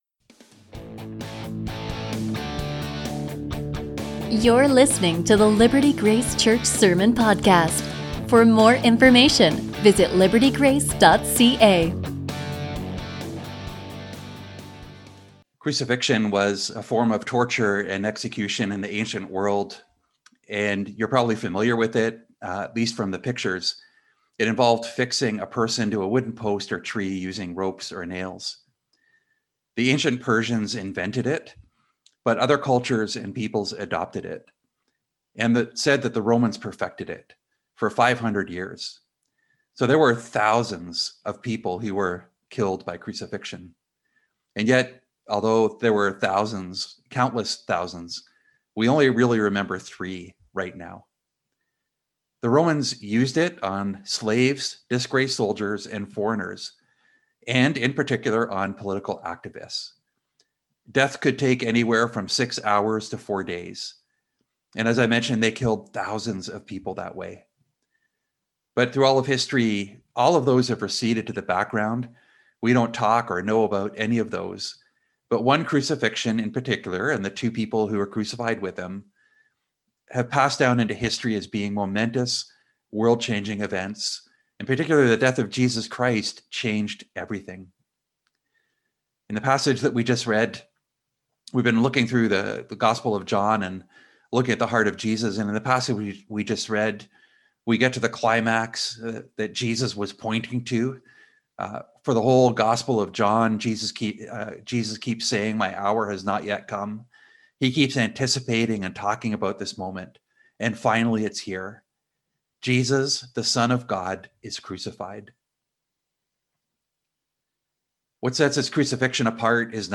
A sermon from John 19:17-42